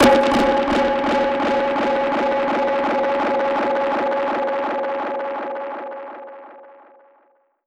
Index of /musicradar/dub-percussion-samples/125bpm
DPFX_PercHit_D_125-01.wav